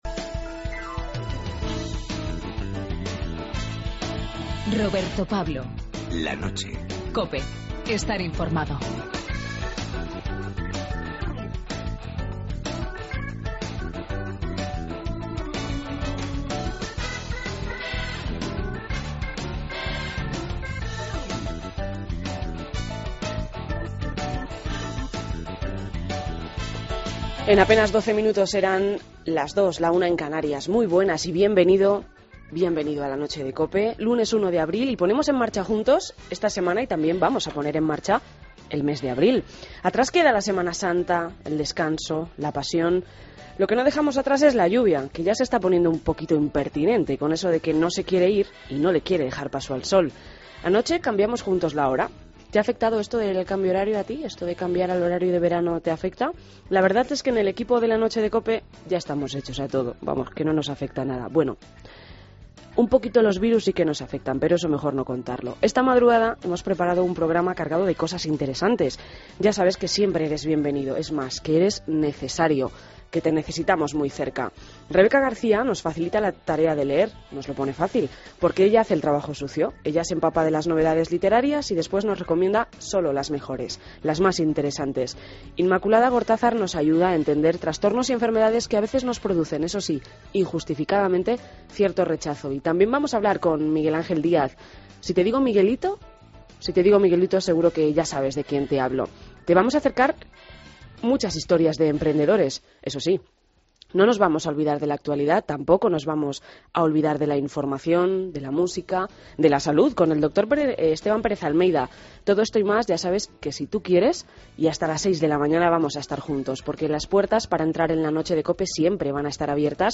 AUDIO: Preguntamos por la calle qué Semana Santa ha sido más especial en tu vida.